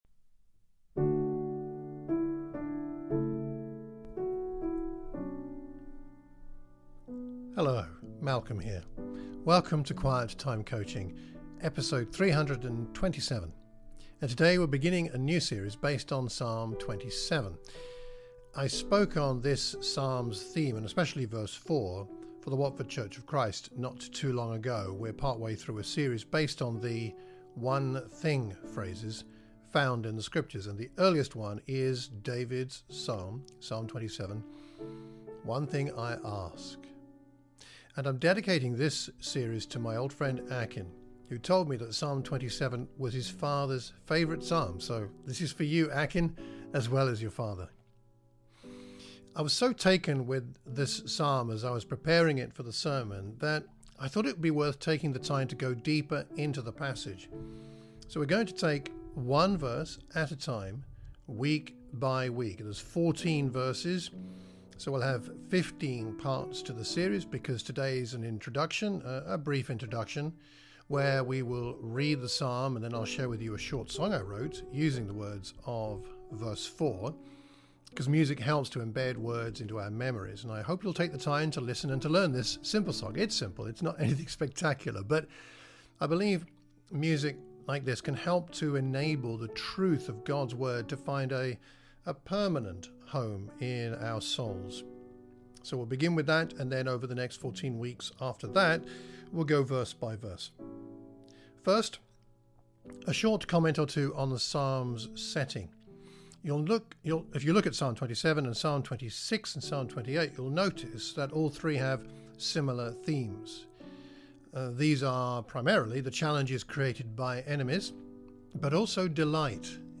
We begin this week with a brief introduction, a reading of the Psalm and then I will share with you a short song I wrote using the words of verse 4.